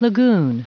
L'accent tombe sur la dernière syllabe: